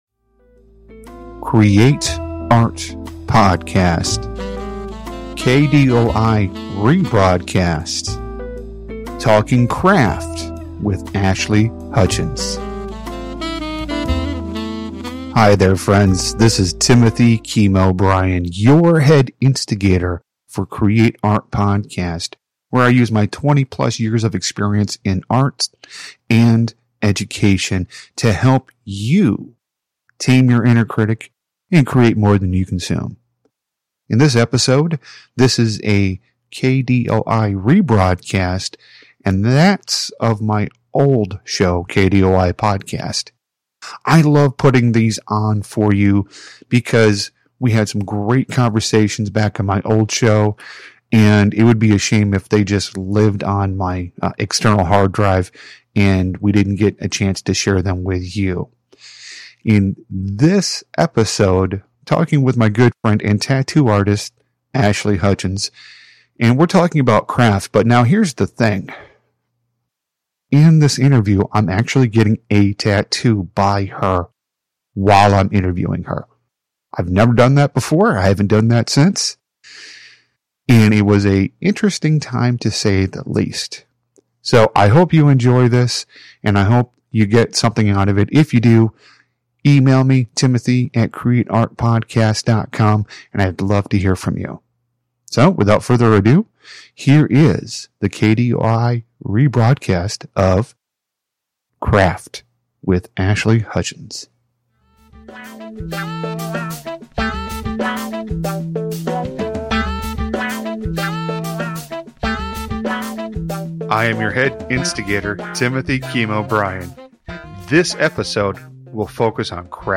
Talking Craft while Being Tattooed This is a K D O I rebroadcast and that's of my old show.
I'm actually getting a tattoo by her while I'm interviewing her.